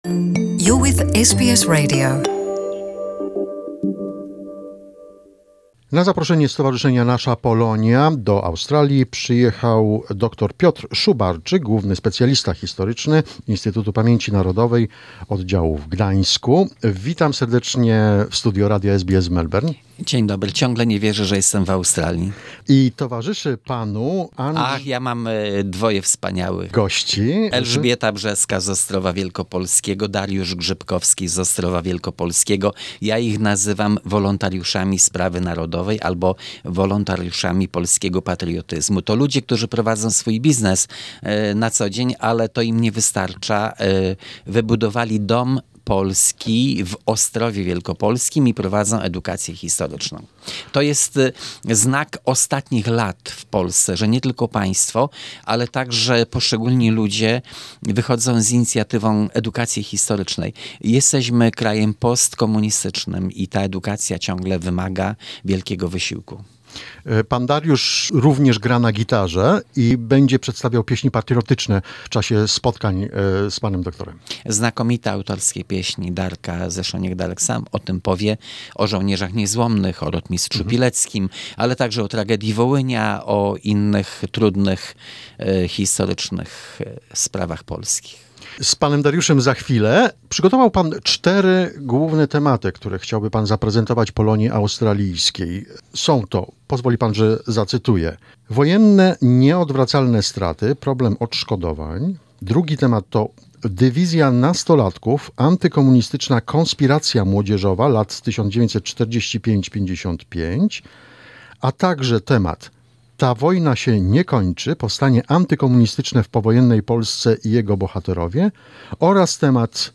at SBS Radio Melbourne